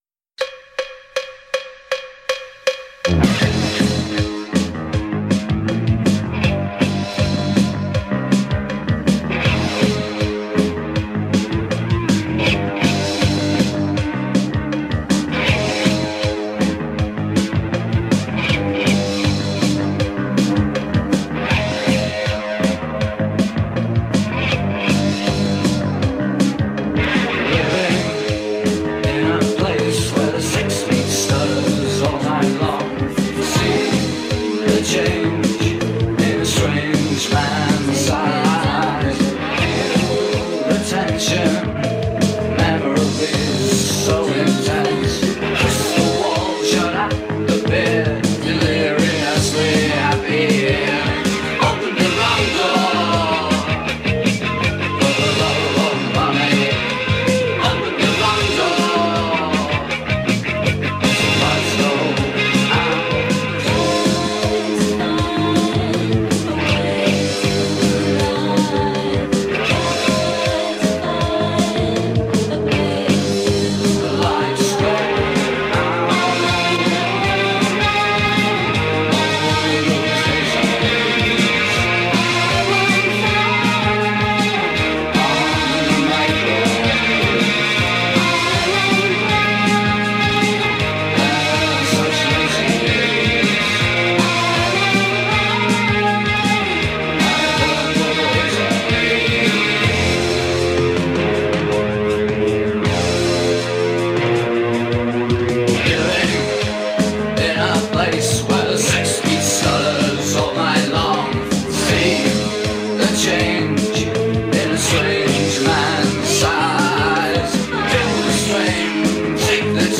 Post-Punk bands
pioneered Goth
When Music in the 80s got broody and dark